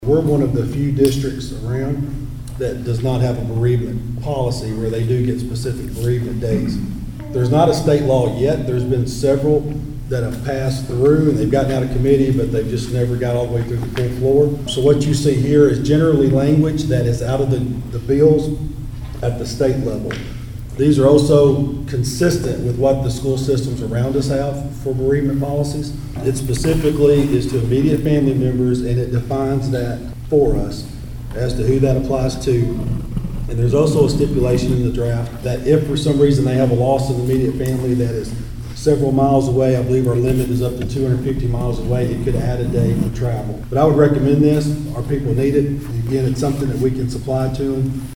Weakley County Director of Schools, Jeff Cupples explains the addition of bereavement leave.